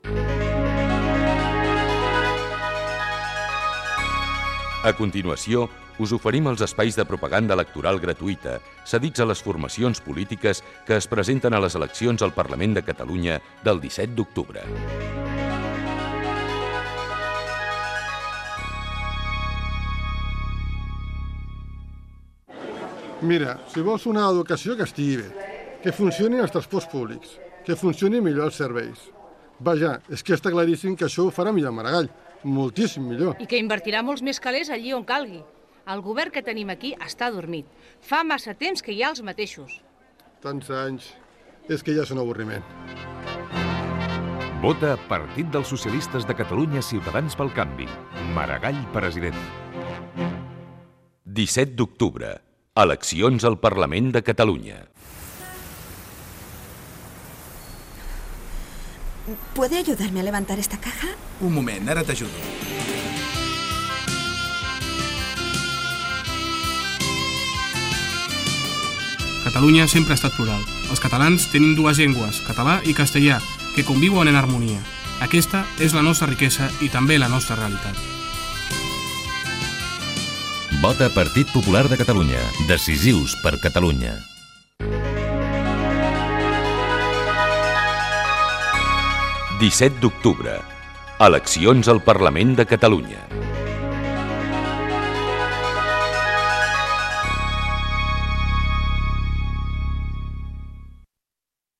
Falques propaganda electoral del Partit Socialista de Catalunya - Ciutadans pel Canvi i del Partit Popular de Catalunya a les eleccions al Parlament de Catalunya.